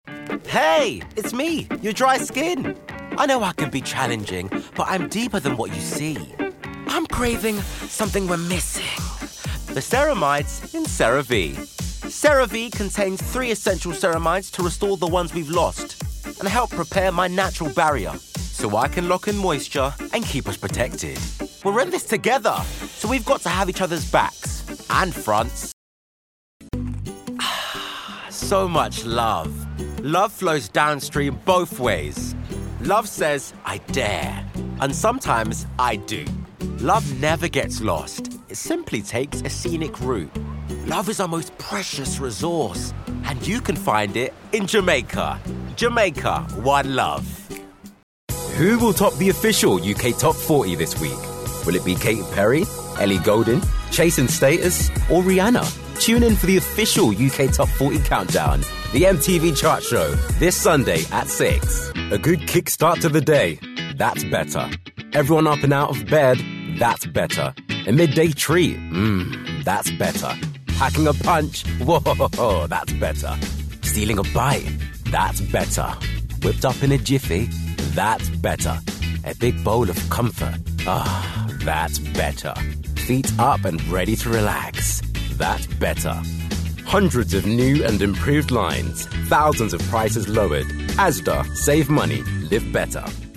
Vocal Styles:
authentic, authoritative
Commercial voiceover demo Reel 2024